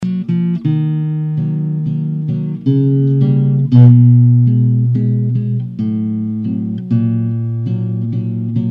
Классическая гитара